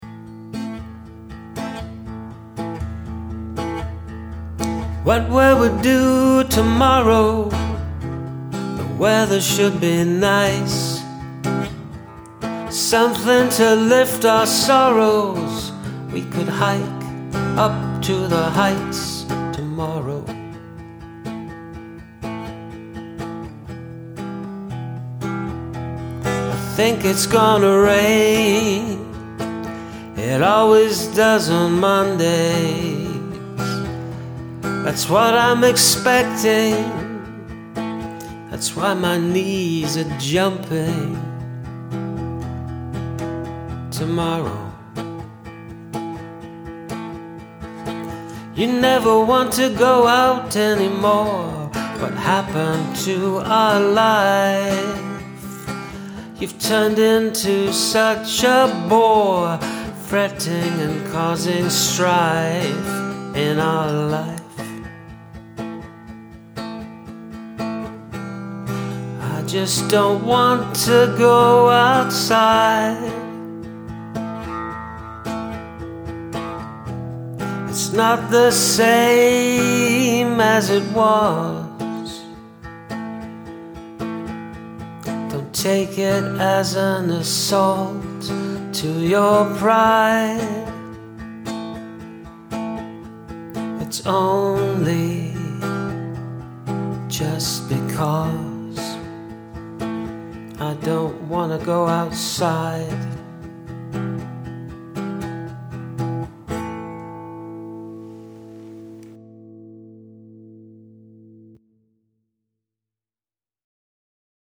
This time I played with different views both lyrically and in terms of tempo. Bit rough but a different take on the prompt
Good use of an energetic strum for Narrator #1 and a more dampened one for their partner.